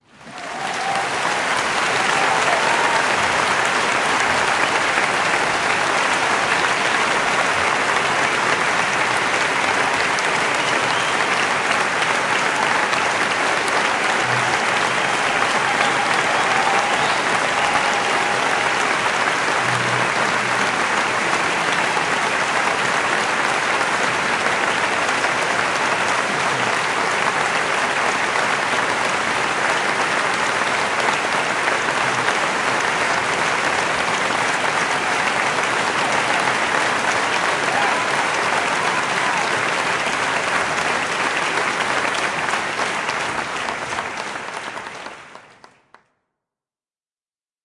FX " 掌声
描述：现场音乐会